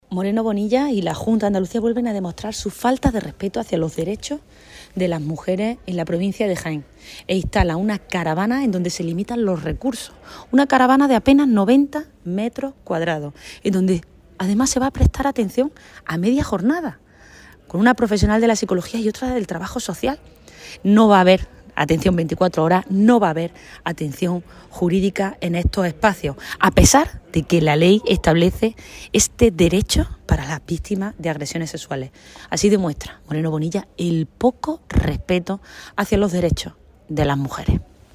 Cortes de sonido